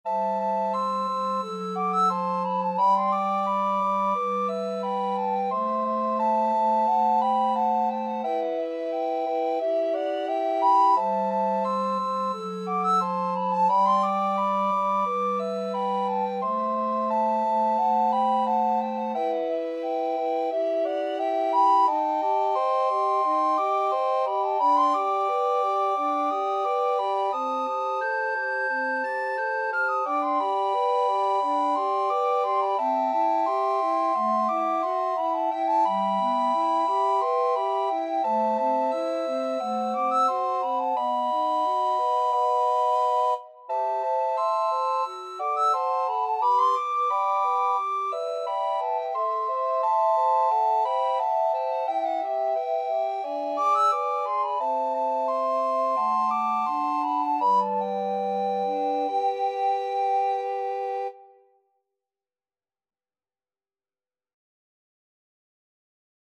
Soprano RecorderAlto RecorderTenor RecorderBass Recorder
4/4 (View more 4/4 Music)
Molto allegro =176
Classical (View more Classical Recorder Quartet Music)